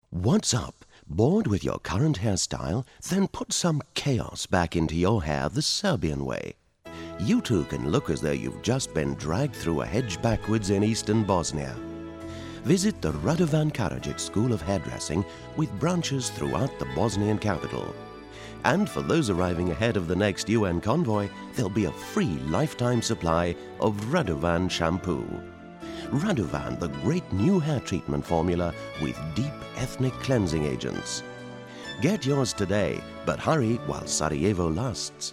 Performer & Producer
sample of his voice